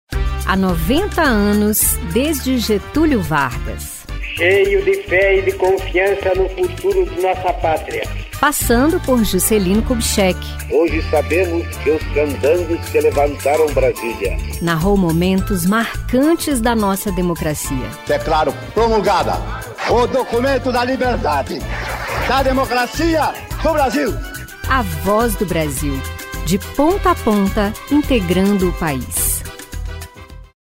Spots e Jingles produzidos pela rede gov e por órgãos do governo federal.